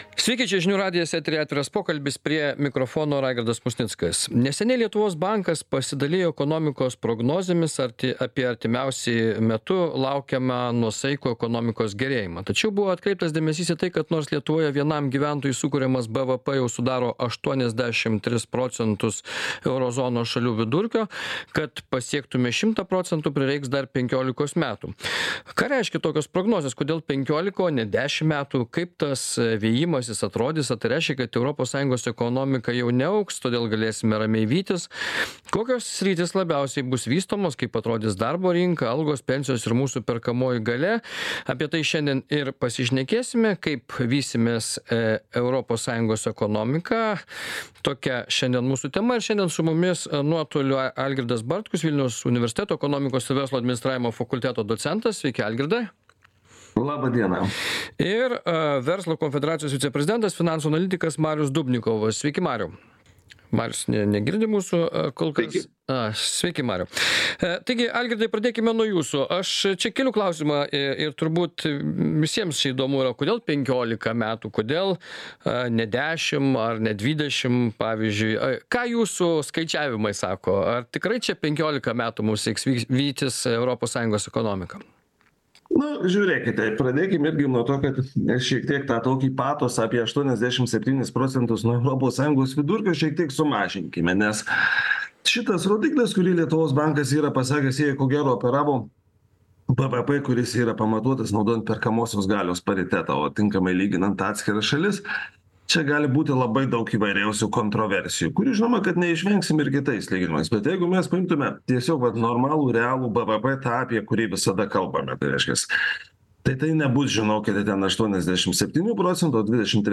Diskutuoja